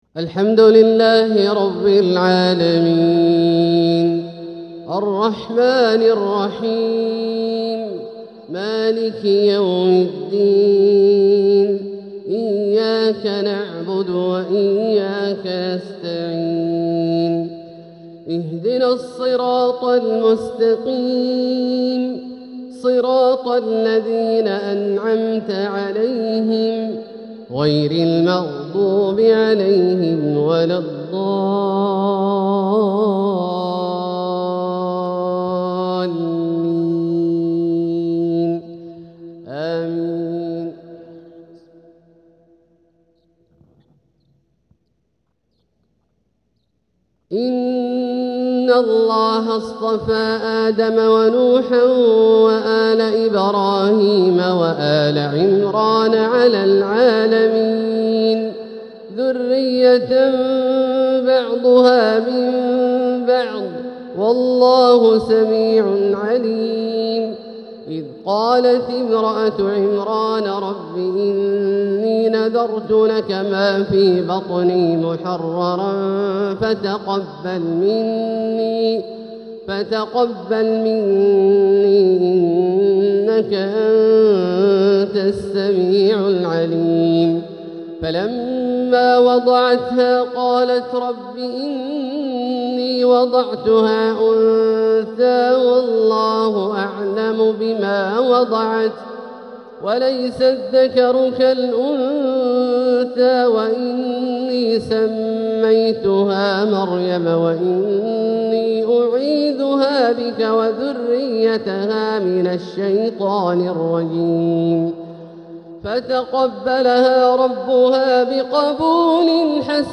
تلاوة مسترسلة عذبة من سورة آل عمران | فجر الأحد 2 صفر 1447هـ > ١٤٤٧هـ > الفروض - تلاوات عبدالله الجهني